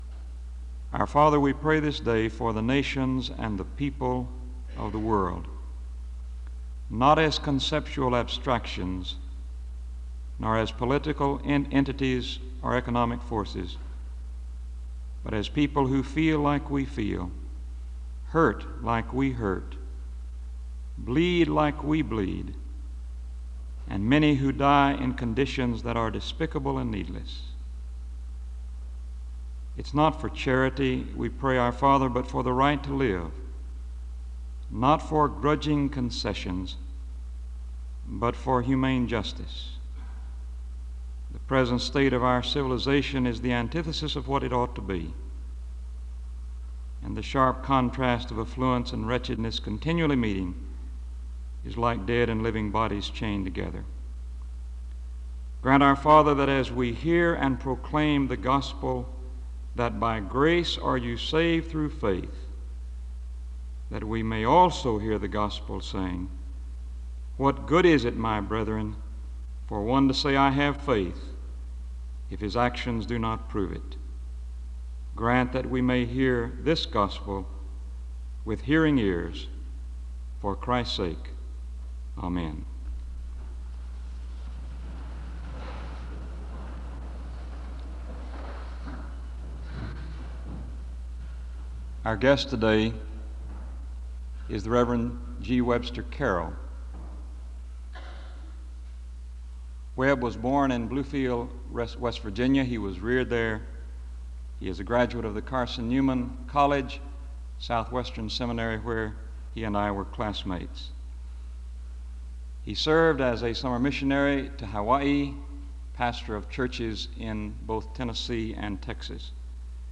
SEBTS Chapel and Special Event Recordings - 1970s